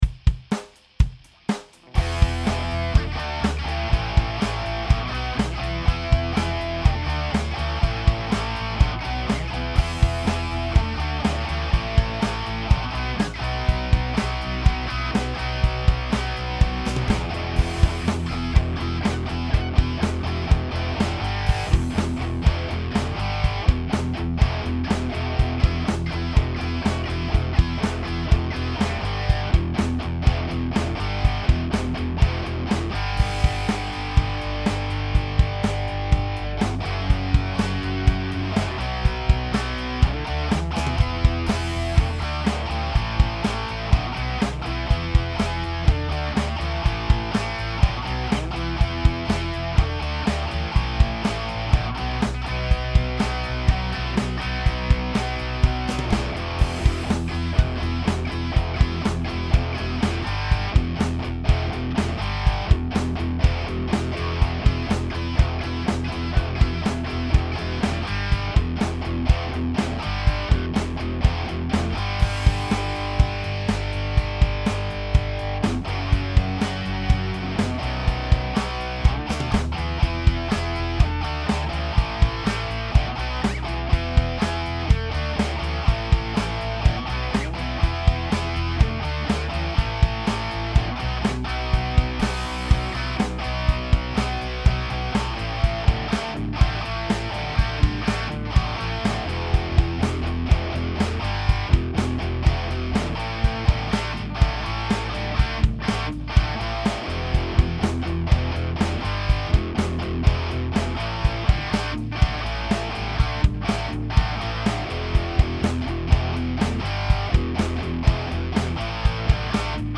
Lead Guitar
Rhythm Guitar
Bass Guitar
Drums